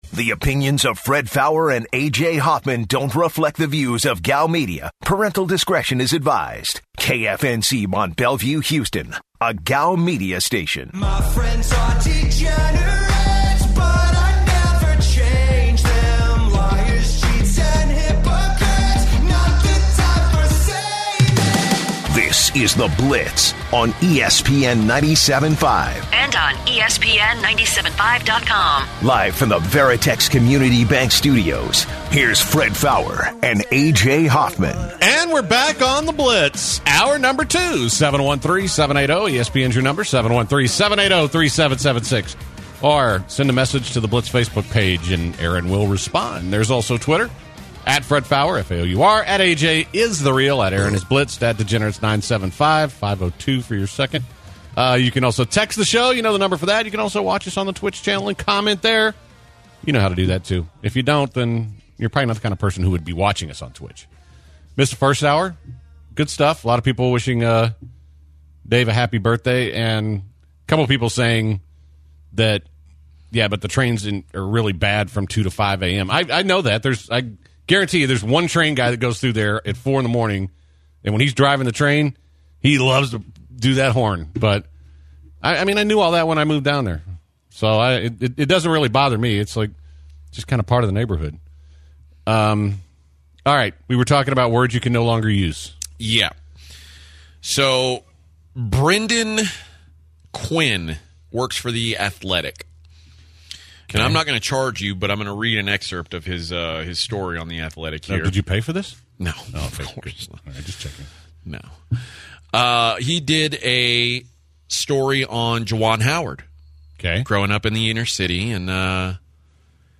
This hour starts off with a conversation about the word ghetto and who can or can not use it. This topic has a lot of callers empassioned and they did not hesitate to call in and voice their opinion.